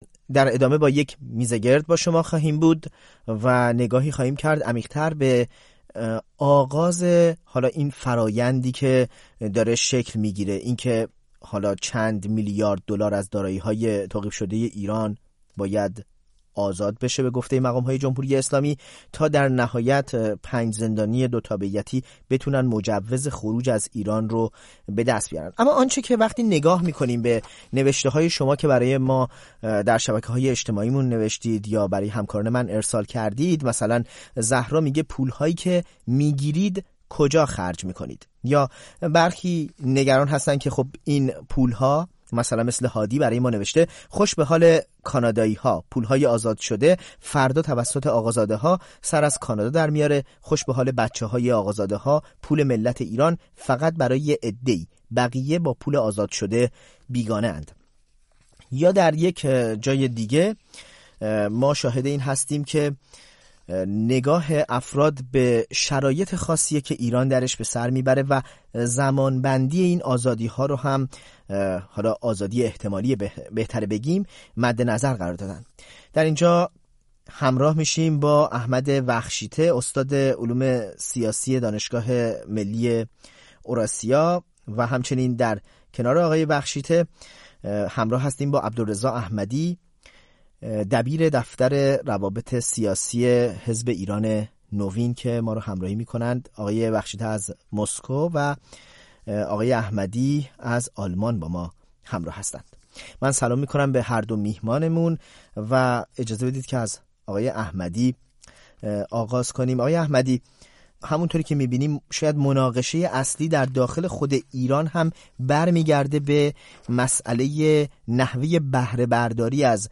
نگاهی به توافق ایران و آمریکا برای مبادله زندانیان دوتابعیتی در قبال آزاد شدن پول های بلوکه ایران و آزموده‌ها و آموزه‌ها در این باره دریک میزگرد.